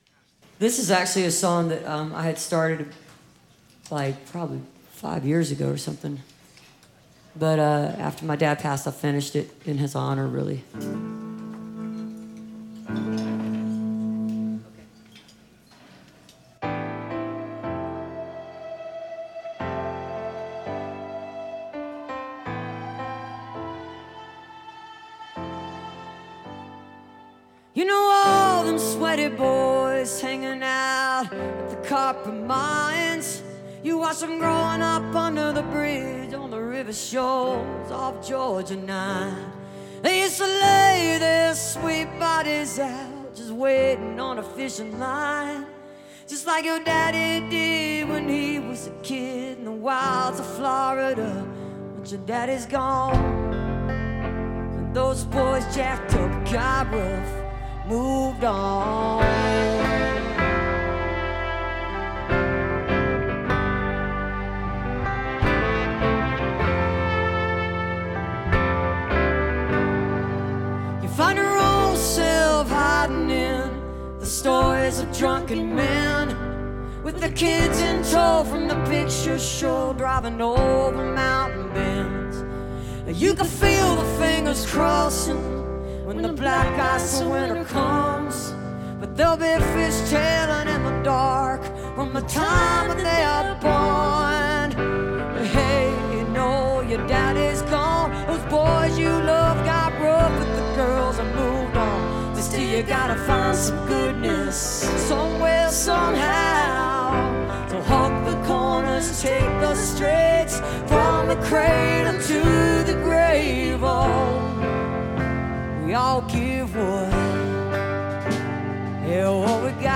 (recorded from the webcast)